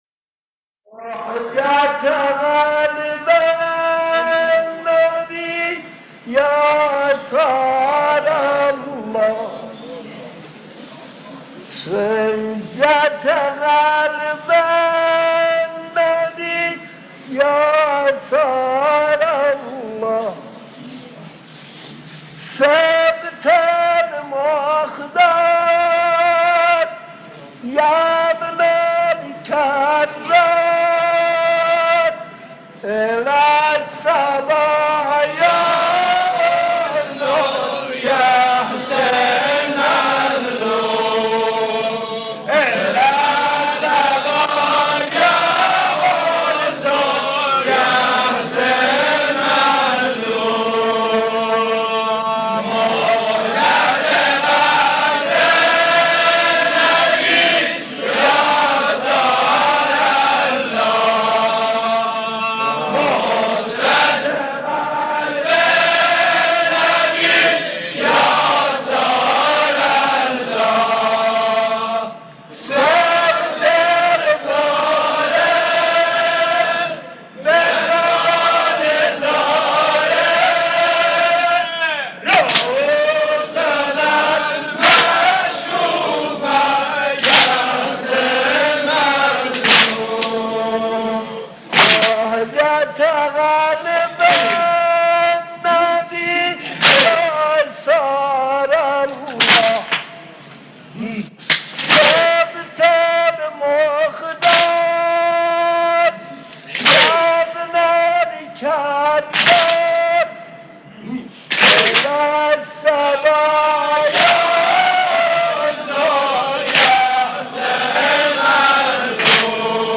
در پرده عشاق، صدای مداحان و مرثیه‌خوانان گذشته تهران قدیم را خواهید شنید که صدا و نفس‌شان شایسته ارتباط دادن مُحب و مَحبوب بوده است.
نوحه‌ای از زبان حضرت زینب (س) خطاب به حضرت سیدالشهدا (ع) در روز اربعین